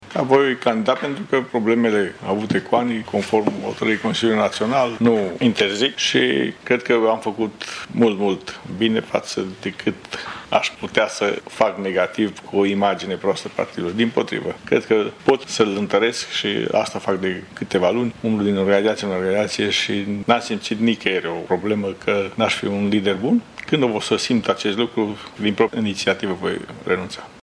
Actualul președinte al PSD Mureș, Vasile Gliga, și-a exprimat intenția de a candida pentru un nou mandat.